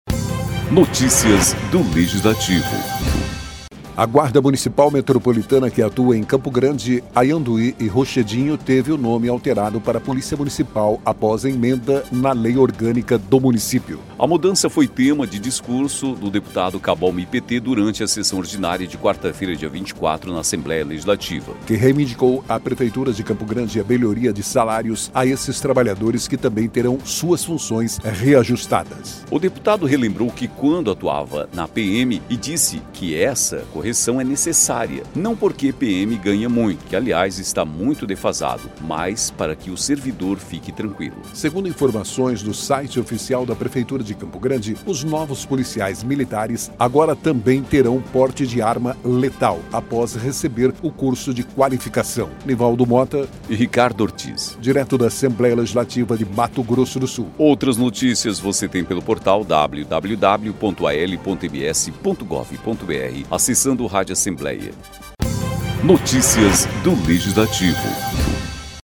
A mudança foi tema de discurso de deputado Cabo Almi (PT), durante a sessão ordinária desta quarta-feira (24) na Assembleia Legislativa, que reivindicou à Prefeitura de Campo Grande a melhoria de salários a esses trabalhadores, que também terão suas funções reajustadas.